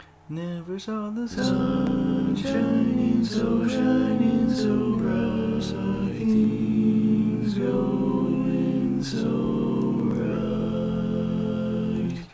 Key written in: F Major
Type: Other male
All Parts mix: